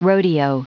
Prononciation du mot rodeo en anglais (fichier audio)
Prononciation du mot : rodeo